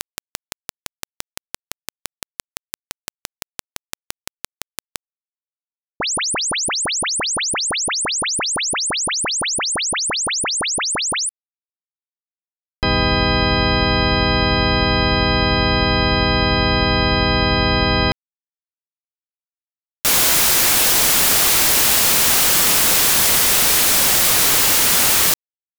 Fledgling voice artist seeking counsel
So, has any one noticed the can-like sound I’ve been talking about?